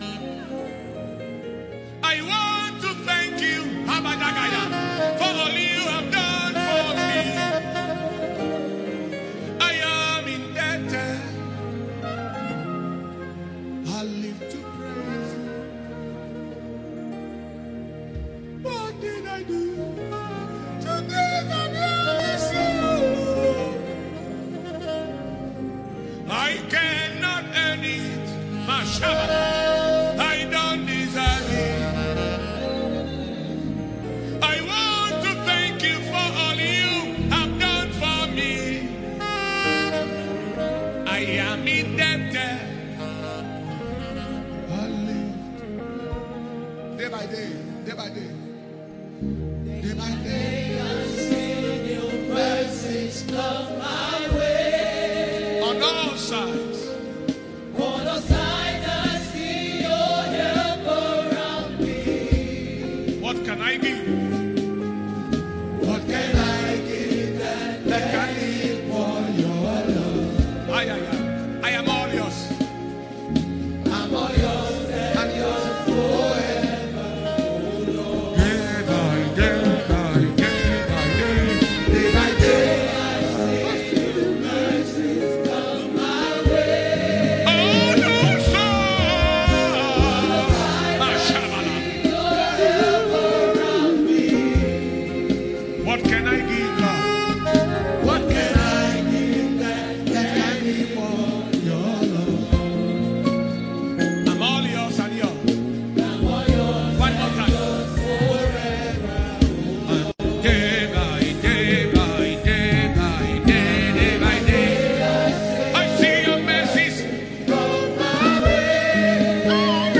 JULY 2020 IMPARTATION SERVICE – SUNDAY, 19TH JULY 2020 ANCHOR SCRIPTURE(S): MATTHEW 6:9-10; HEBREWS 11:6 MESSAGE TOPIC: EFFECTIVE PRAYER COMPONENTS